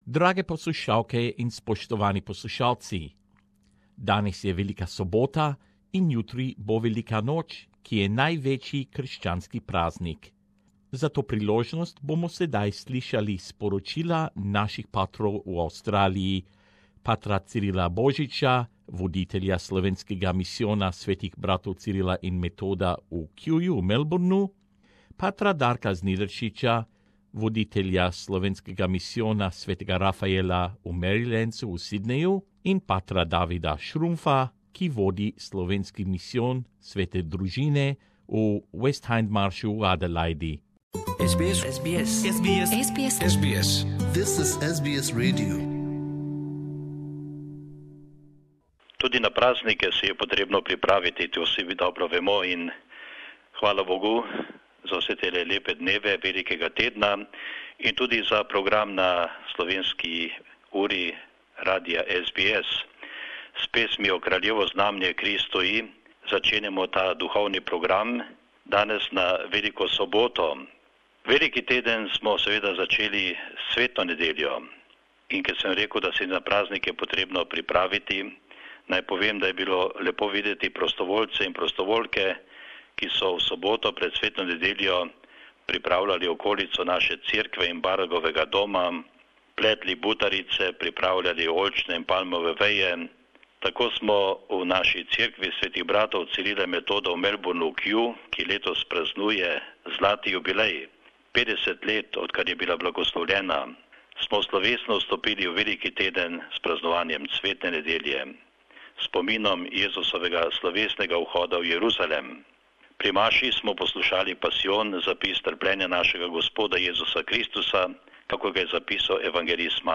Velika noč je največji krščanski praznik. Ob tej priložnosti prisluhnimo velikonočnim sporočilom naših slovenskih frančiškansih patrov v Avstraliji